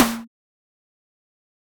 snare01.mp3